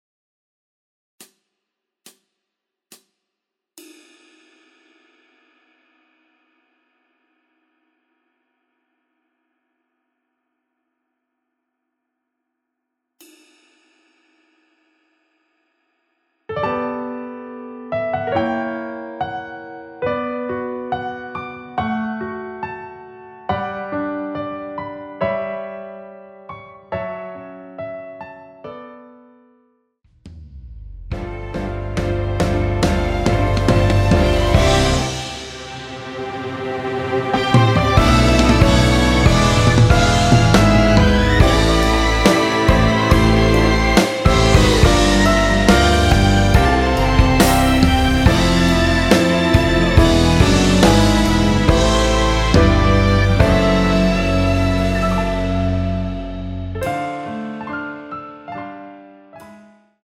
원키에서(-8)내린 MR입니다.
앞부분30초, 뒷부분30초씩 편집해서 올려 드리고 있습니다.
중간에 음이 끈어지고 다시 나오는 이유는